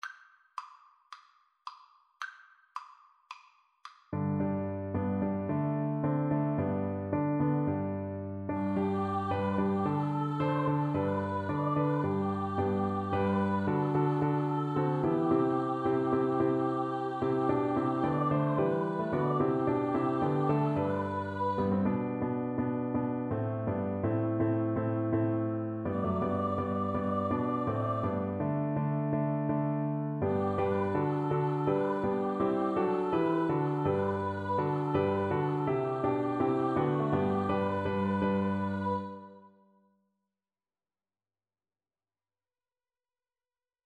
Free Sheet music for Choir (SATB)
Allegro moderato = c. 110 (View more music marked Allegro)
4/4 (View more 4/4 Music)
Classical (View more Classical Choir Music)